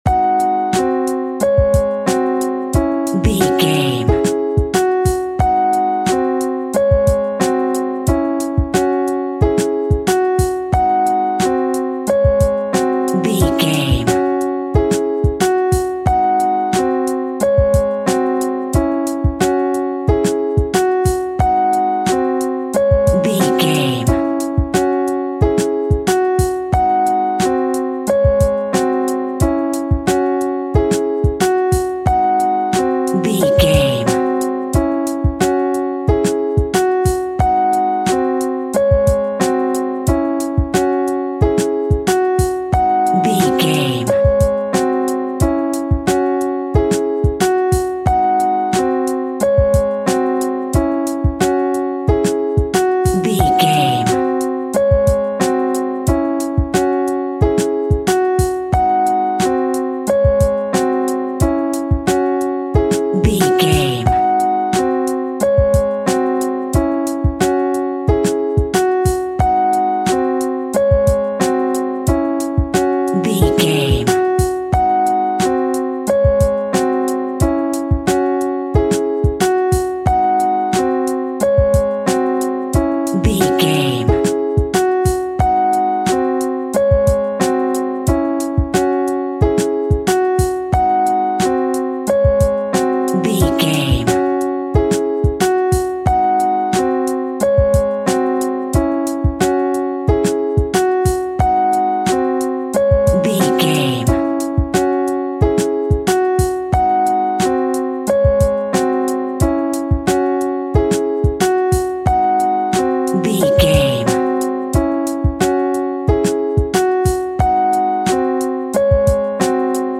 House Rapping.
Ionian/Major
funky house
disco funk
electric guitar
bass guitar
drums
hammond organ
fender rhodes
percussion